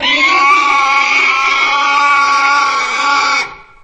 boar_anomaly_2.ogg